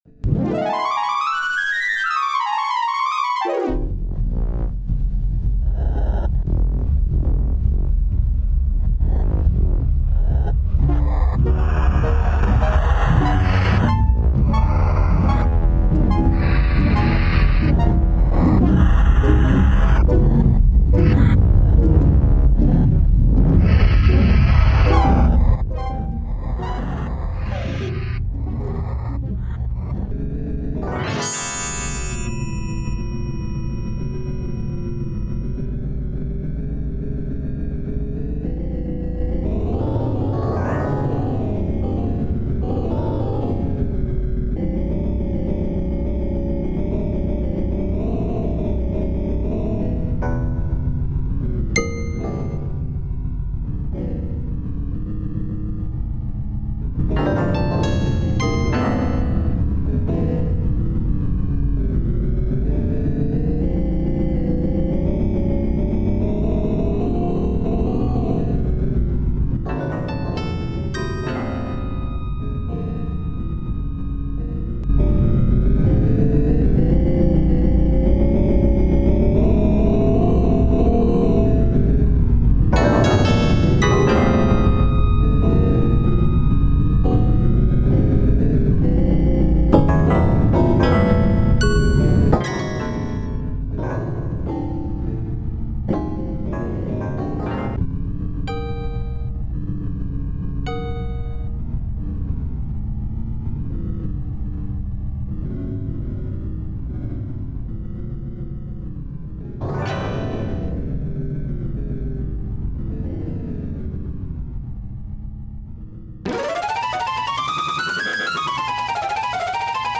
Electroacoustic
Fixed sounds